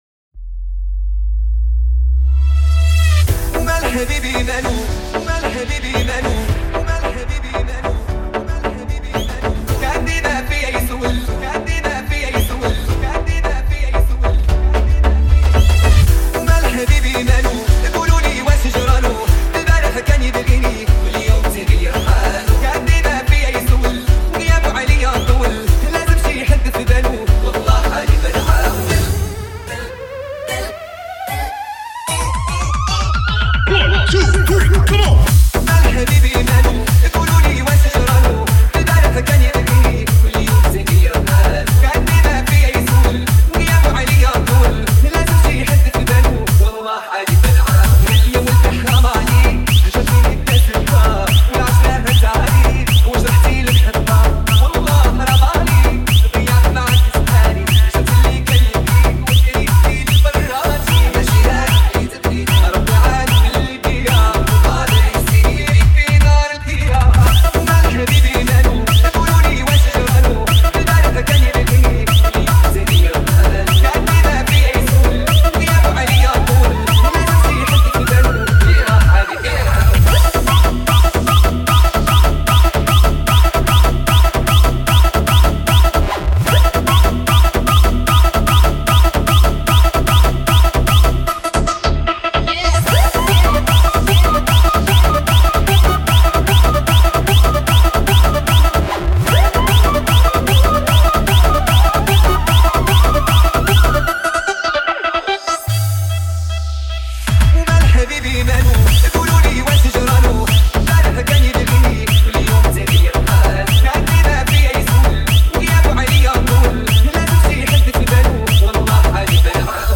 Dance Mix